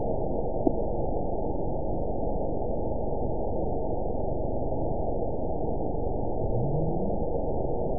event 922676 date 03/06/25 time 20:32:45 GMT (3 months, 1 week ago) score 9.53 location TSS-AB02 detected by nrw target species NRW annotations +NRW Spectrogram: Frequency (kHz) vs. Time (s) audio not available .wav